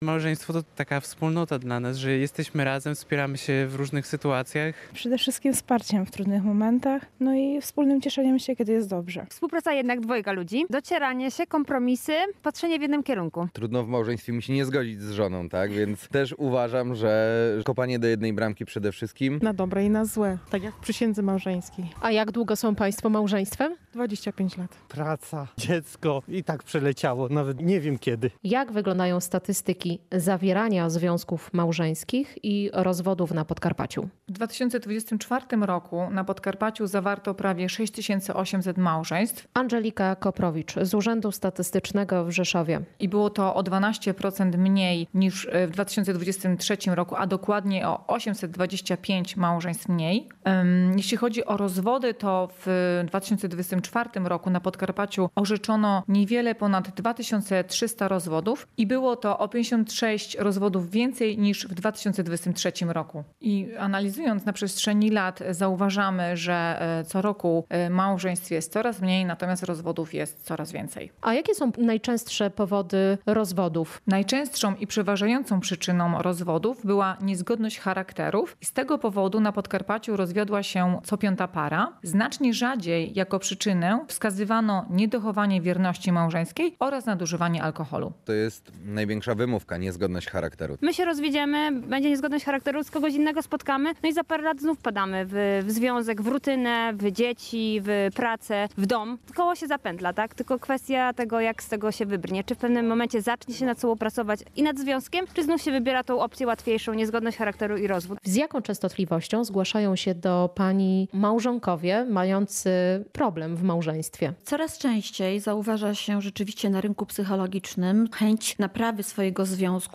Spotkania i wydarzenia dla rodzin • Relacje reporterskie • Polskie Radio Rzeszów